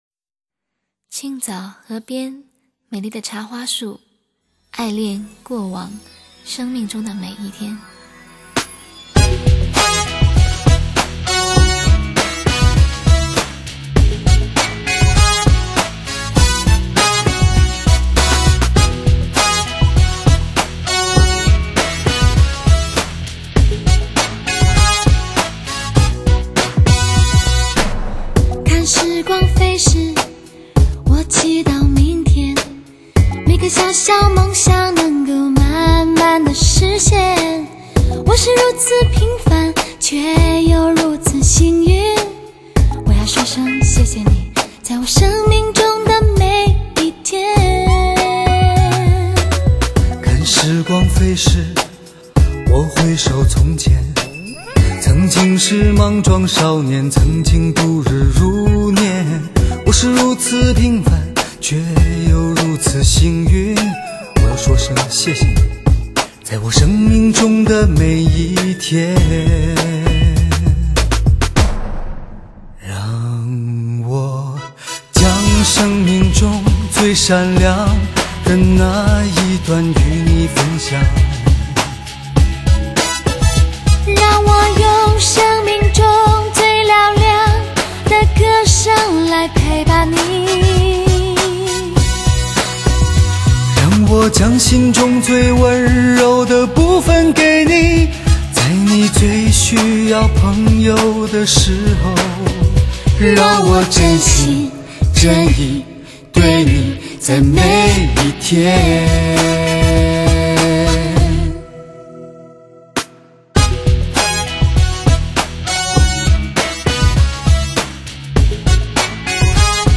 如此能渲染情绪的两把声音，诠释起情歌来总是格外动人。